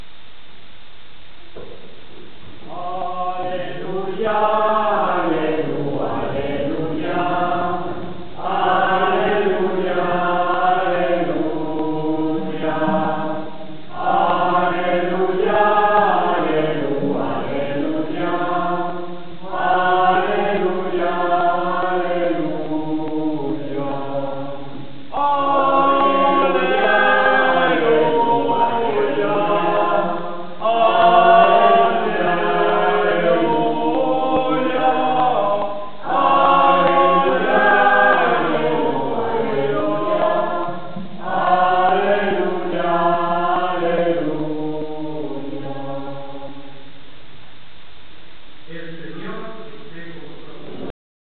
El Coro "Cantores de Sandoval" tiene un repertorio de canciones religiosas.
Grabación en directo en la misa   (sentimos la calidad regular de la grabación)